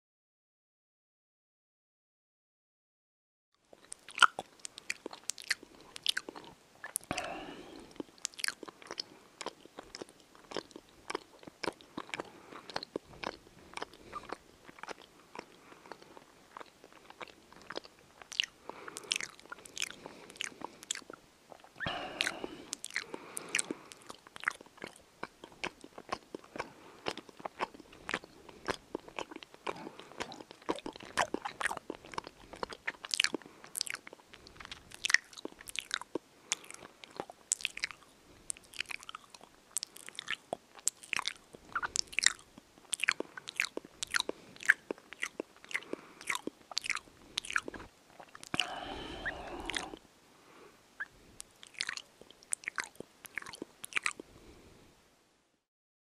جلوه های صوتی
دانلود صدای خوردن غذا 1 از ساعد نیوز با لینک مستقیم و کیفیت بالا